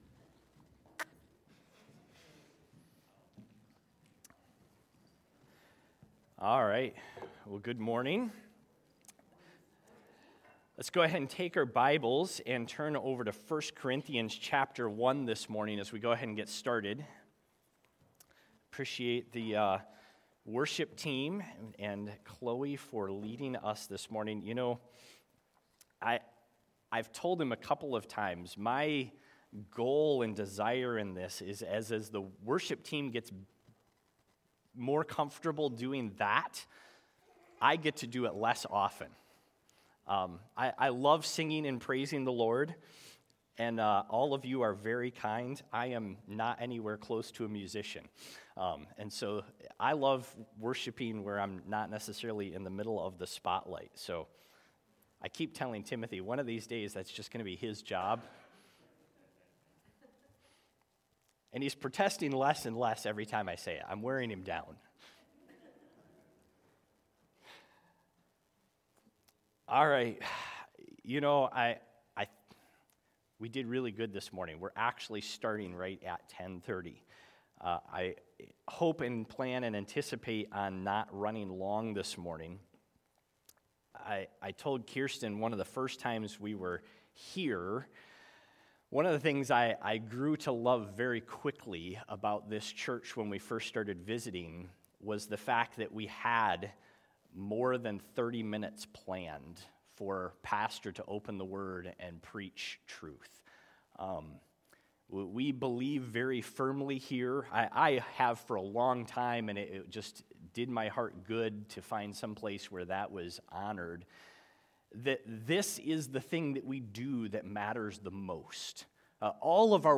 Sermons by CBCP